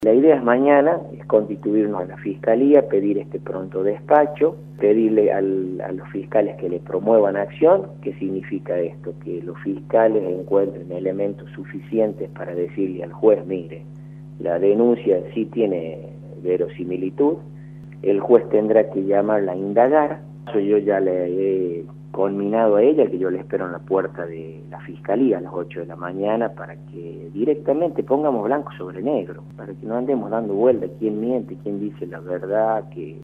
Guillermo Galván, diputado provincial, por Radio La Red
En diálogo con Salí por La Red por Radio La Red,  el legislador habló sobre la denuncia presentada en la Fiscalía por el manejo de fondos públicos por parte de la senadora Teresita Luna durante su paso por la Vicegobernación.